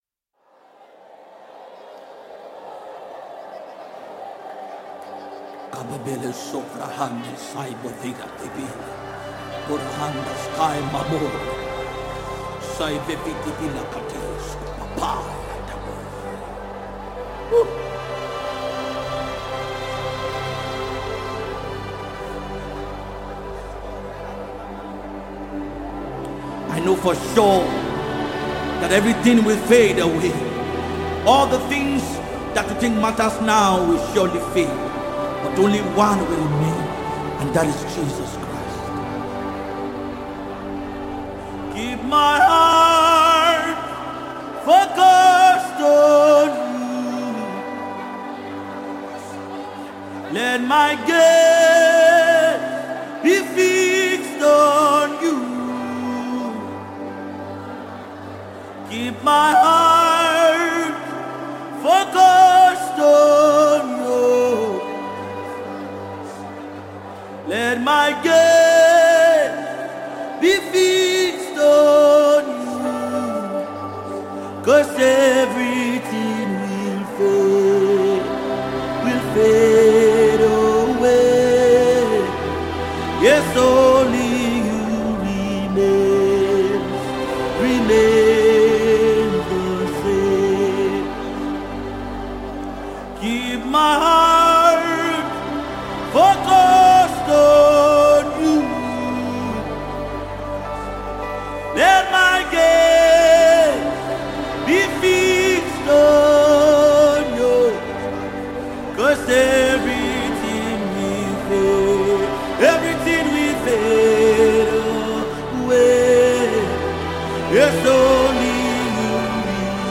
GospelMusic
Gospel Spiritually filled singer and songwriter
gospel sound